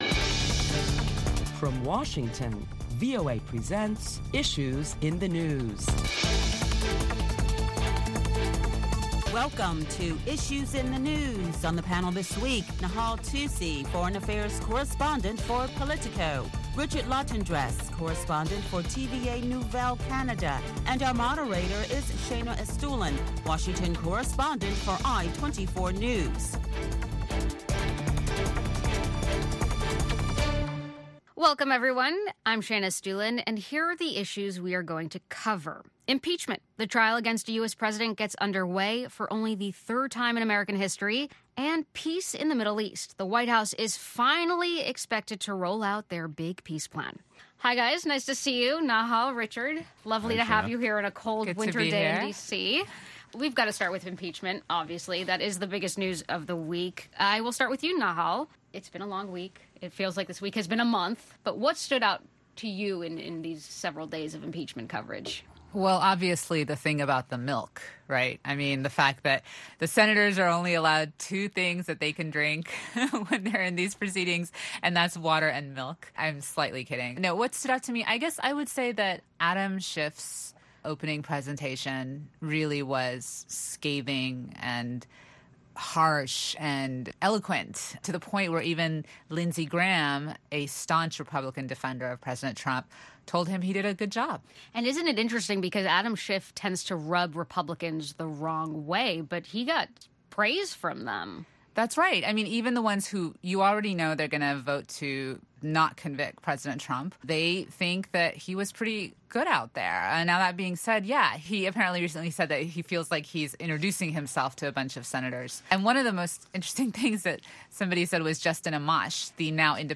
Listen to a panel of prominent Washington journalists as they deliberate the latest top stories of the week that include the Democrats making impassioned pleas to the Senate for the removal of President Trump from office.